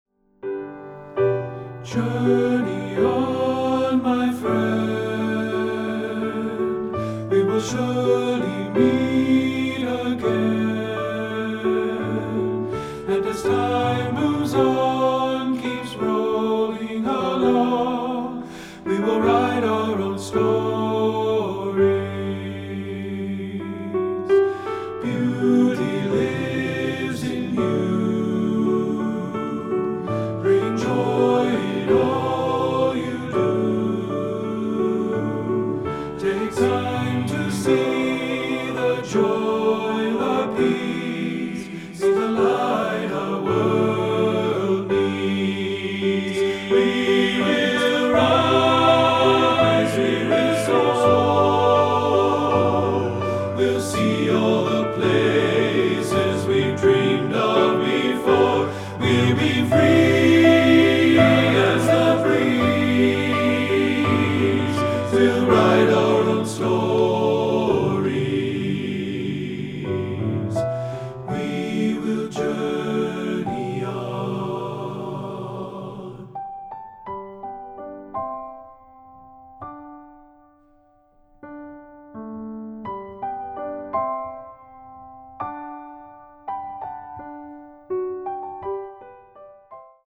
Choral Male Chorus